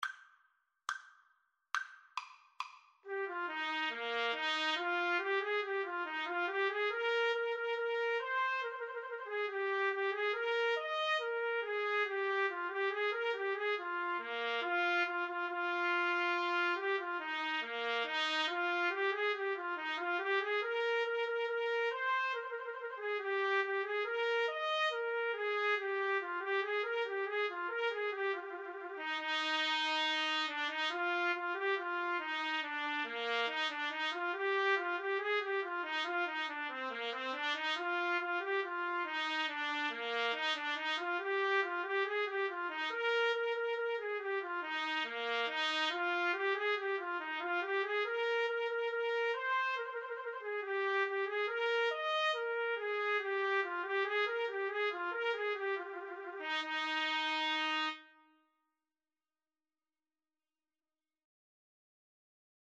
Allegro Maestoso = 70 (View more music marked Allegro)
Trumpet Duet  (View more Easy Trumpet Duet Music)